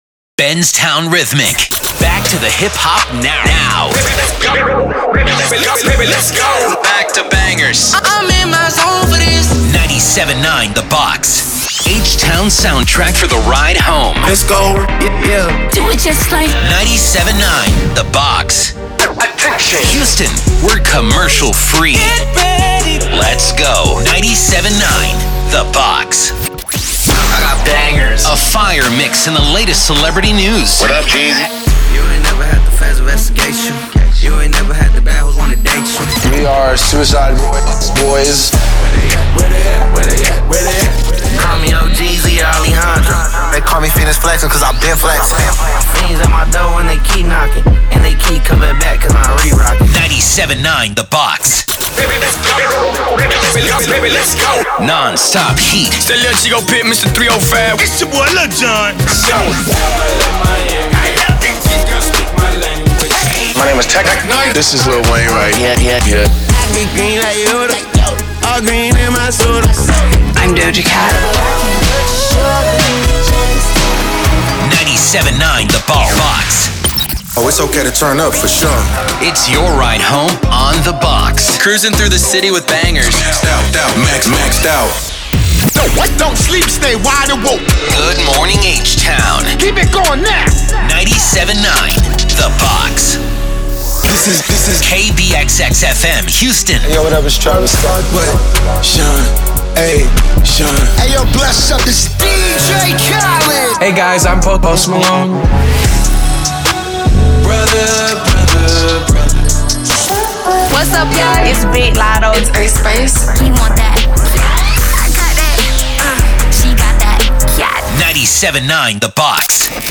RHYTHMIC
Containing shelled produced promos and sweepers, ramploops, branded song intros, artist IDs, listener drops, song hooks, musicbeds, individual imaging workparts, and more.
Composites-Rhythmic-October-2025.wav